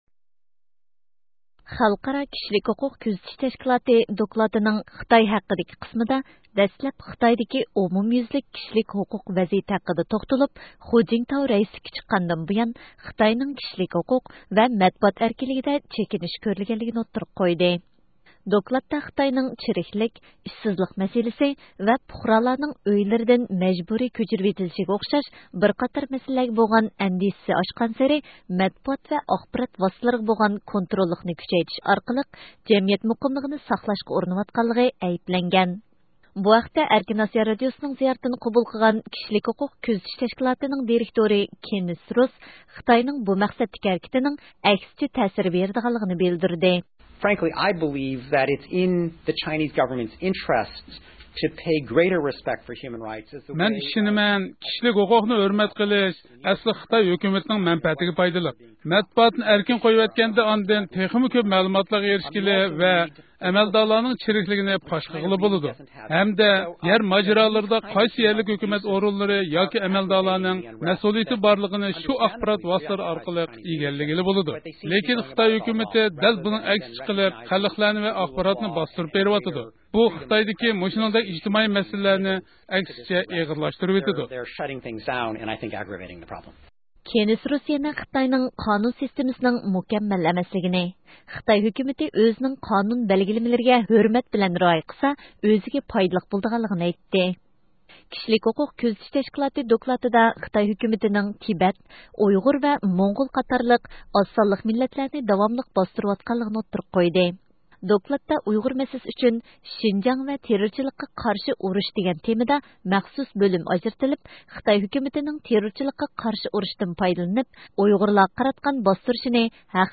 بۇ ھەقتە ئەركىن ئاسىيا رادىئوسىنىڭ زىيارىتىنى قوبۇل قىلغان كىشىلىك ھوقۇق كۆزىتىش تەشكىلاتىنىڭ دېرىكتورى كېننېس روس (Kenneth Roth) خىتاينىڭ بۇ مەقسەتتىكى ھەرىكىتىنىڭ ئەكسىچە تەسىر بېرىدىغانلىقىنى بىلدۈردى: